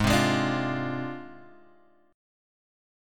G# 6th Flat 5th